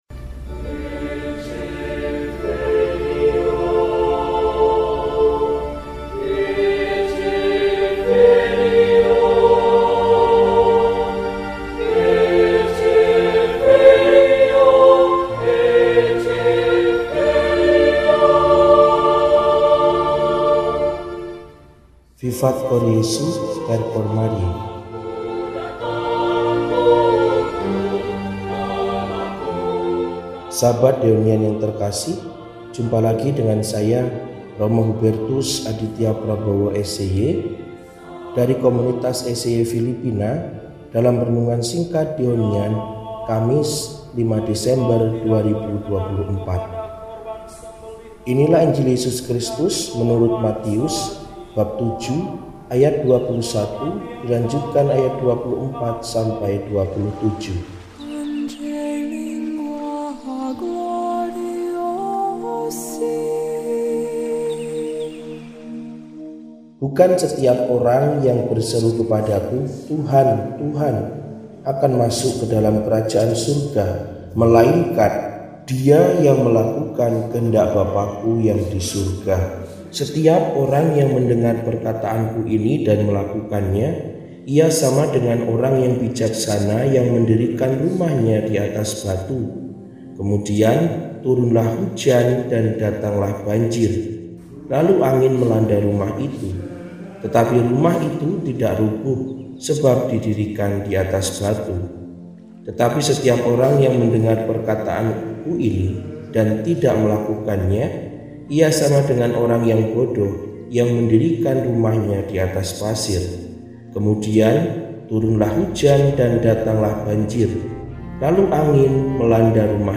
Kamis, 05 Desember 2024 – Hari Biasa Pekan I Adven – RESI (Renungan Singkat) DEHONIAN